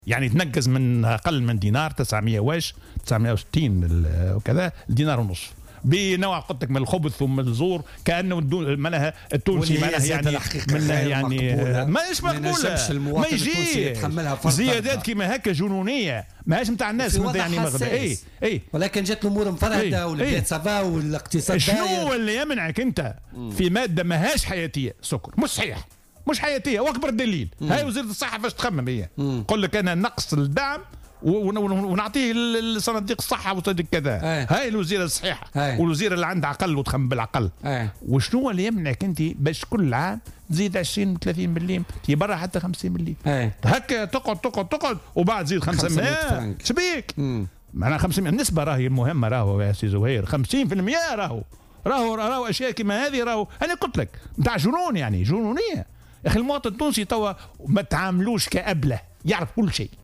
وأضاف الخبير خلال استضافته اليوم في برنامج "بوليتيكا" أن هذه الزيادة تعتبر جنونية وخبيثة، حيث تم التخلي نهائيا عن السكر "الصبة" واستبداله بسكر معلب، والترفيع في الأسعار بنسبة كبيرة.